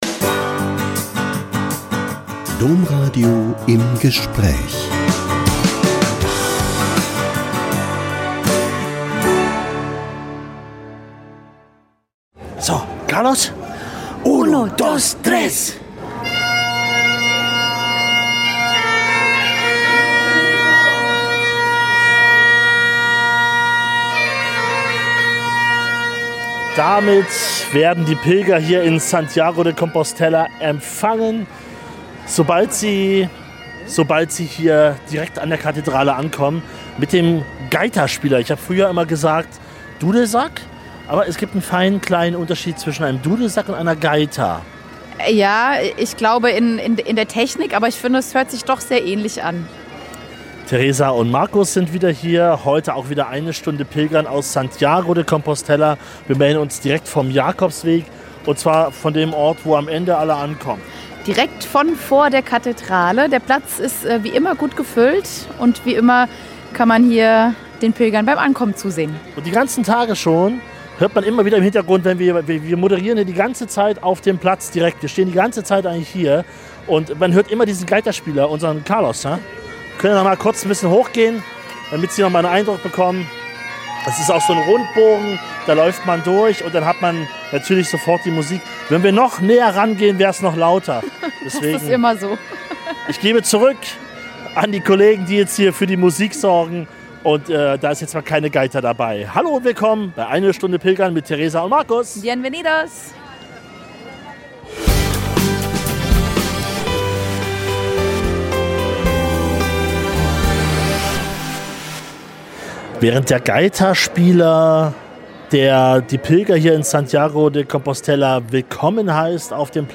Pilgern - DOMRADIO aus Santiago de Compostela Folge 4 ~ Im Gespräch Podcast